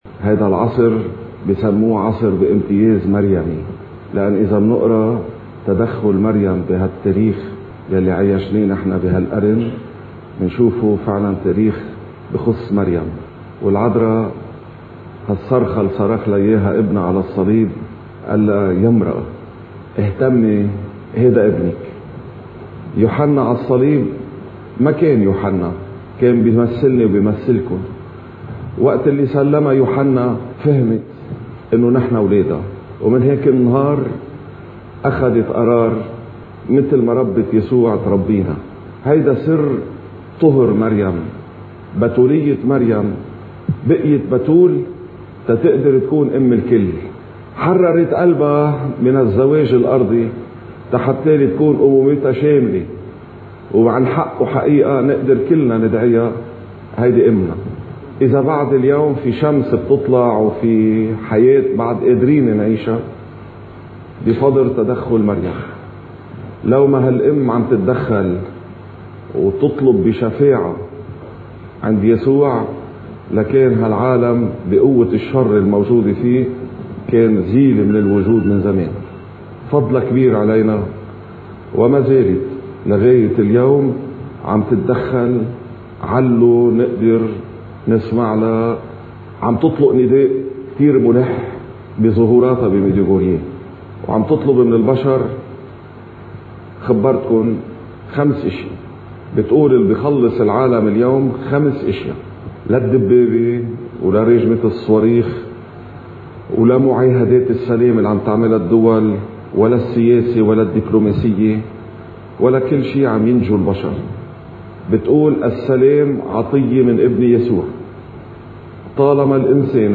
مقتطف من عظة الراهب المريمي